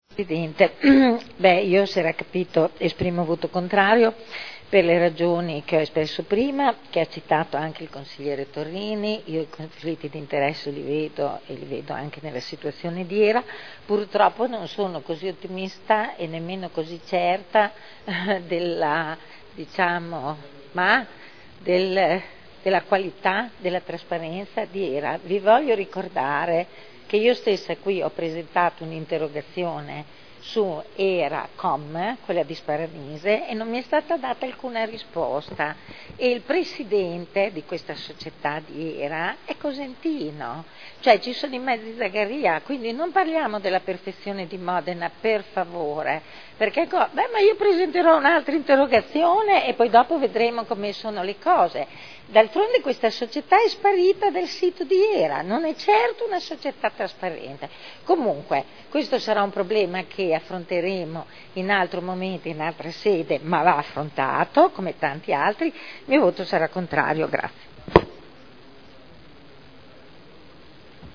Eugenia Rossi — Sito Audio Consiglio Comunale